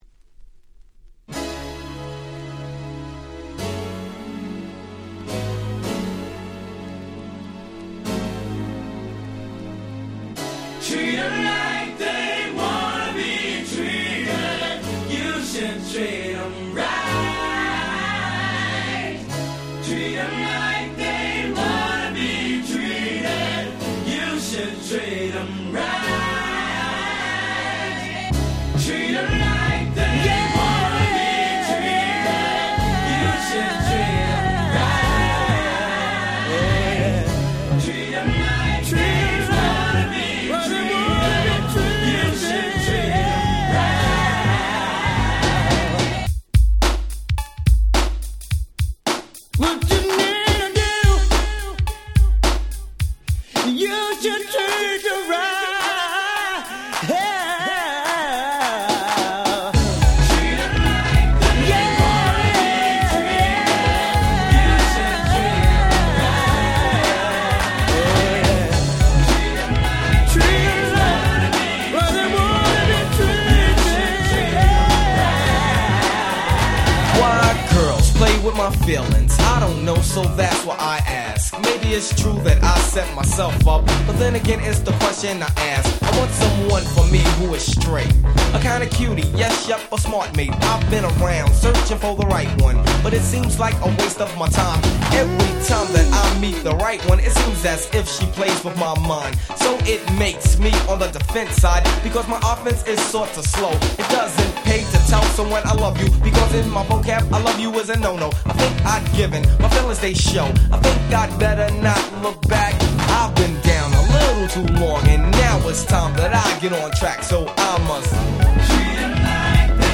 90' Super Nice New Jack Swing !!
説明不要の鉄板New Jack Swingです！！